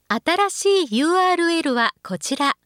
ホームページ作成で利用できる、さまざまな文章や単語を、プロナレーターがナレーション録音しています。